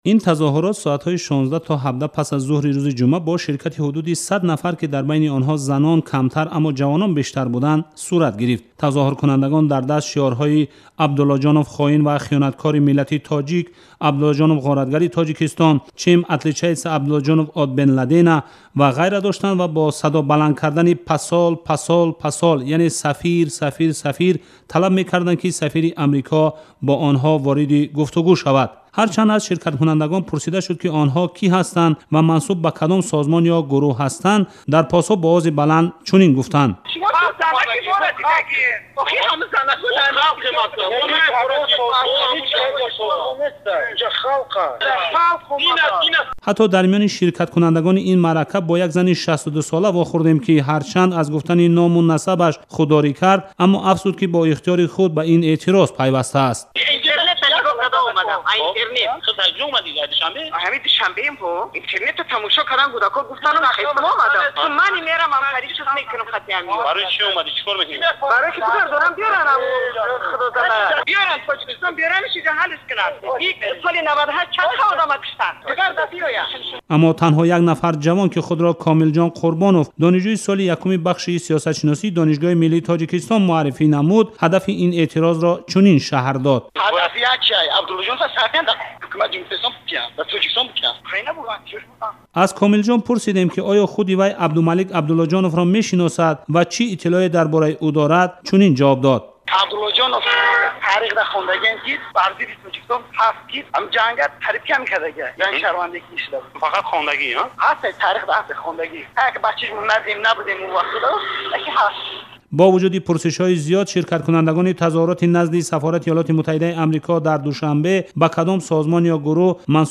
Садоҳо аз гирдиҳамоӣ дар назди сафорати Амрико